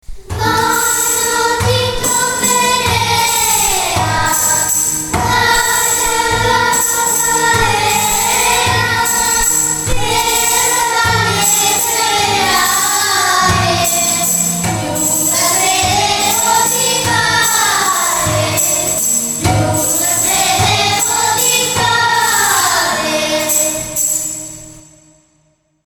Partitura de la canción popular de Benimodo
The traditional music